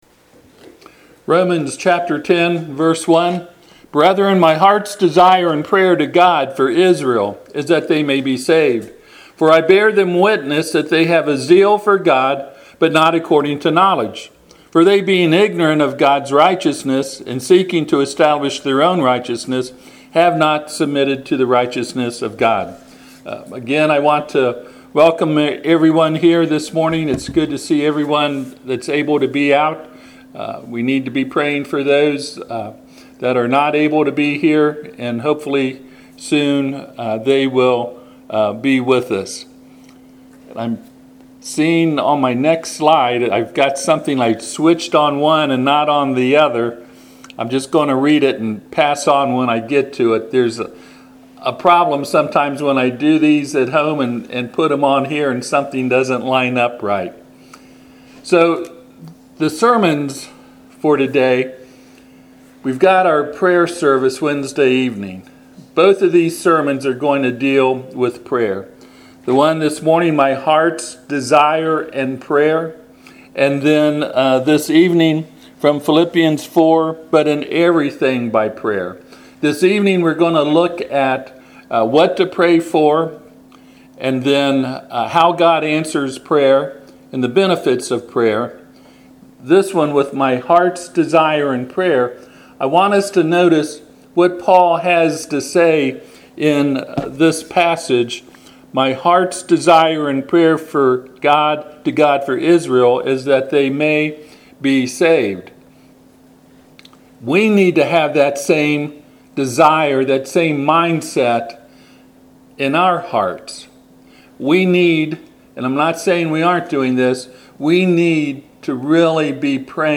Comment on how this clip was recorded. Passage: Romans 10:1-3 Service Type: Sunday AM